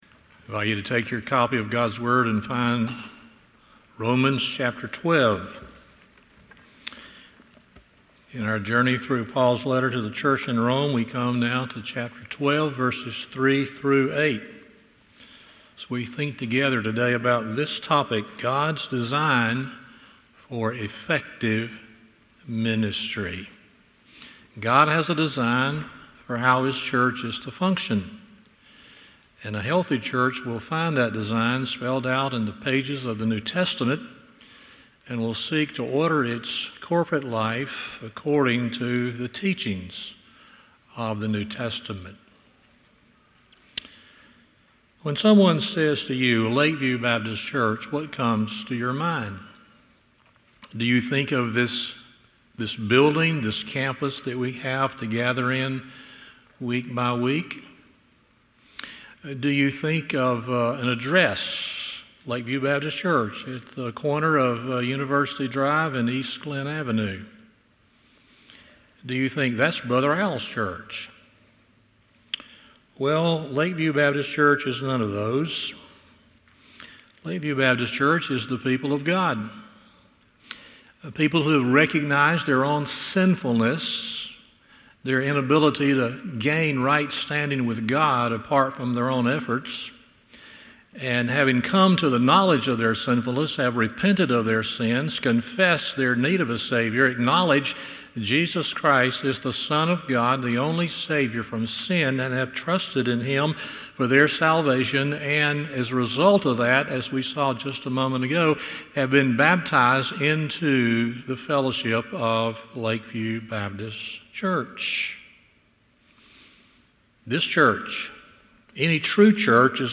Romans 12:3-8 Service Type: Sunday Morning 1.